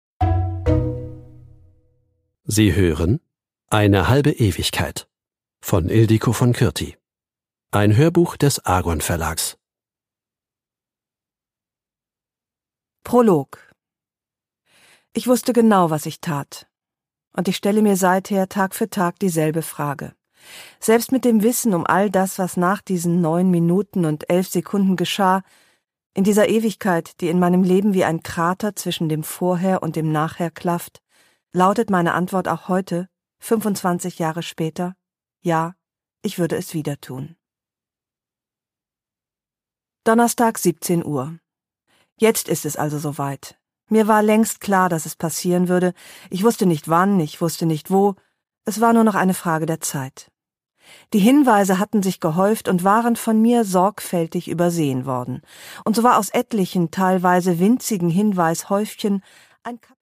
Produkttyp: Hörbuch-Download
Gelesen von: Ildikó von Kürthy